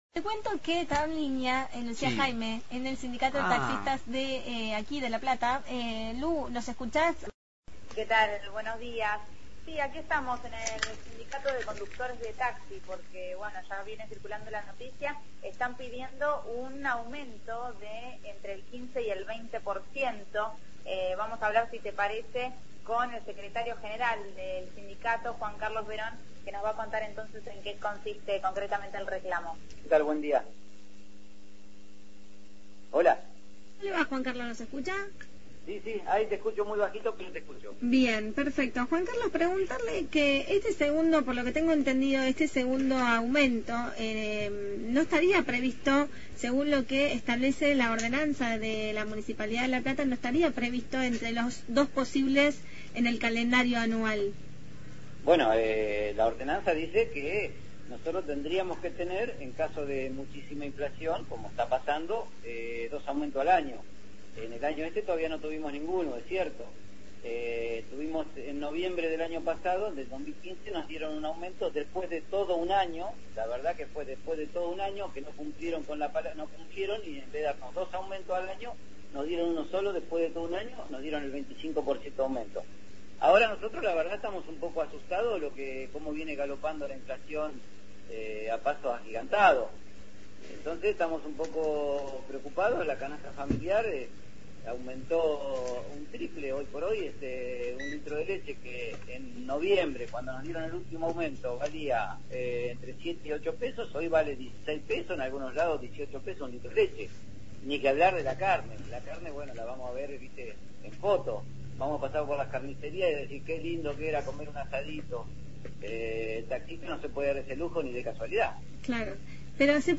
MÓVIL/ Reclamo de taxistas por aumento de tarifas – Radio Universidad